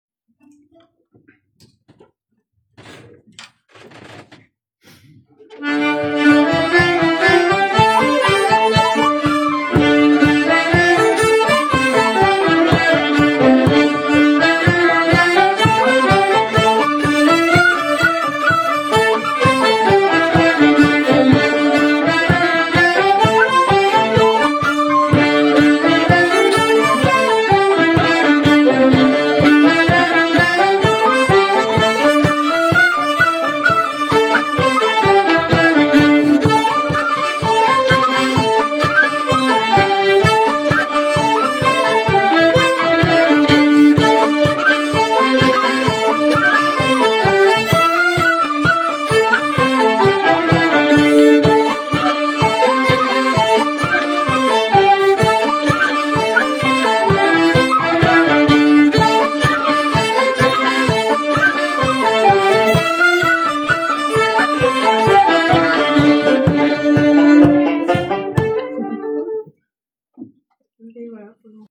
Les slow sessions de Paris » 2025 » mai
Ce 13 Mai au Bizart
reel